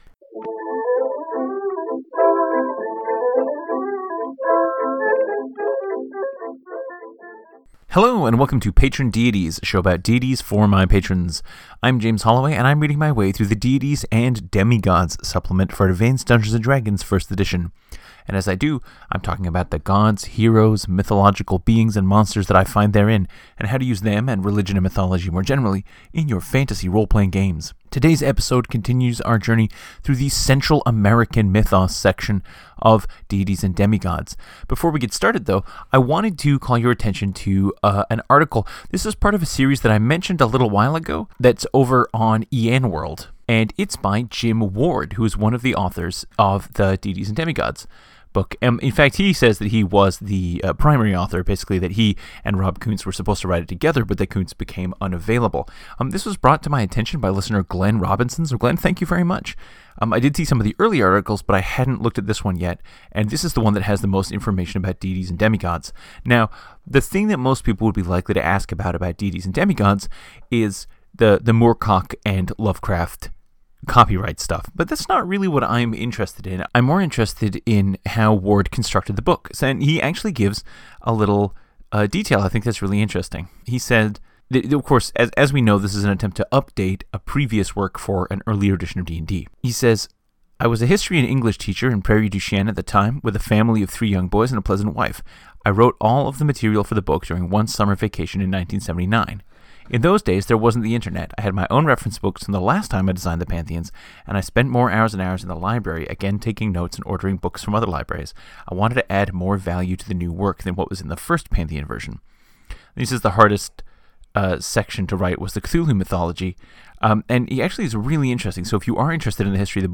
Do you like ... hearing me try to pronounce Nahuatl names?
Plus: a reading from the Popol Vuh in which a kid hiding inside a blowgun gets his head ripped off by a bat.